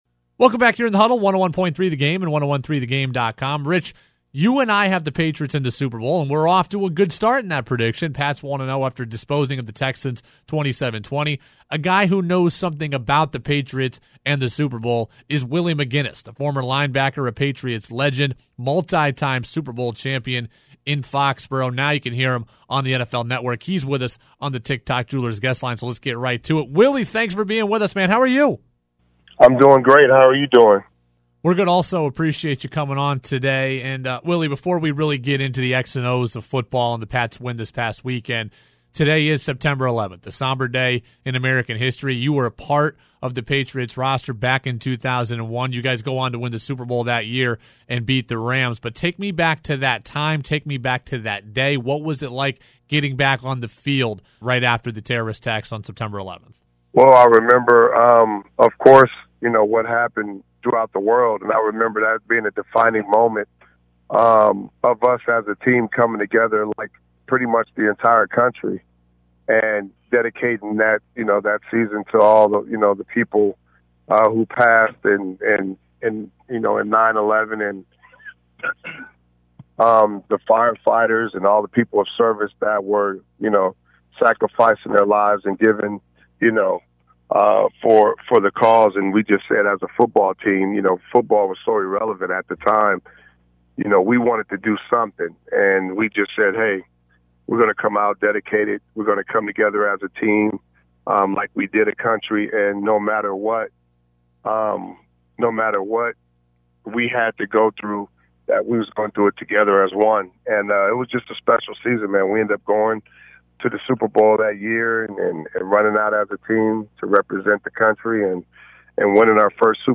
He joined ‘The Huddle’ on Tuesday to talk about the Pats Week 1 win over the Texans, why Bill Belichick assistants can’t seem to win when they leave New England, why he didn’t fear tackling Jerome Bettis in his career and he reminisced on what it was like to play after the Sept. 11 terrorist attacks.